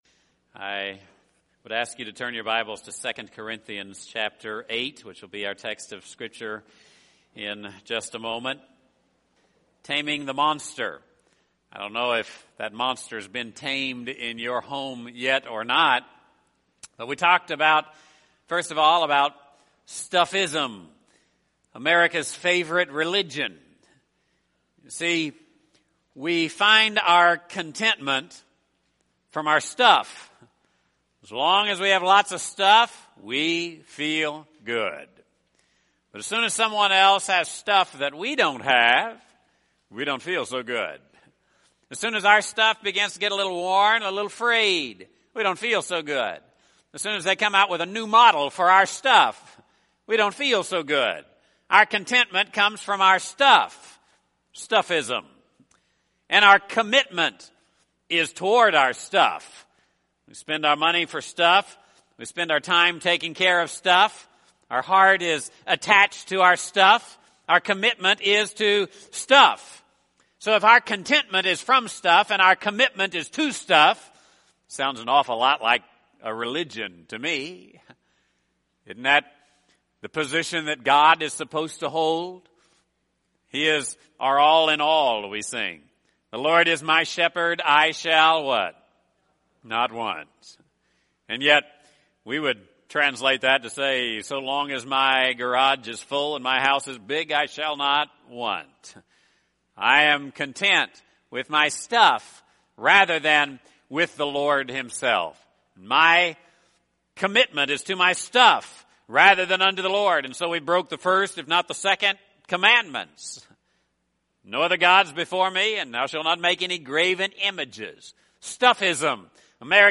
One of the most prevalent problems of American Christianity is its materialism. “Stuffism” has become America’s favorite religion!Word for the World is offering a four-sermon CD series called Stuff: Taming the Monster.